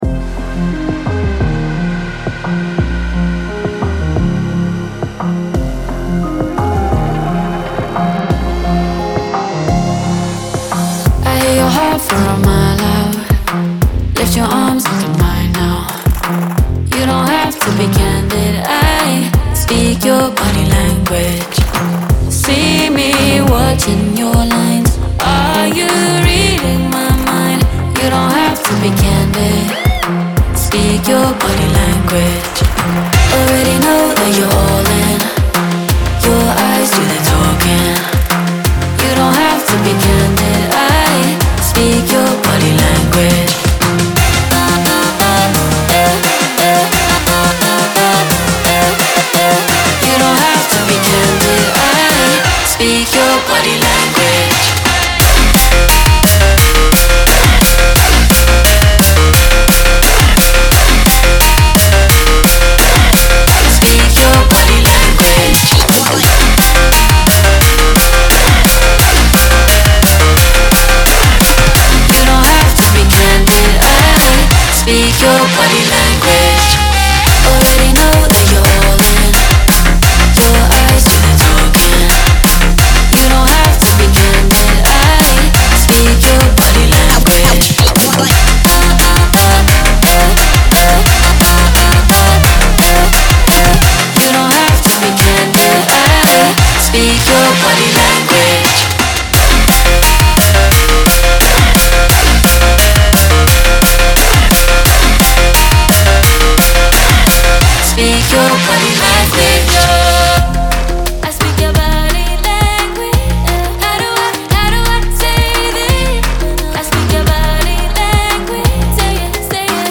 BPM174-174
Audio QualityPerfect (High Quality)
Drum and Bass song for StepMania, ITGmania, Project Outfox
Full Length Song (not arcade length cut)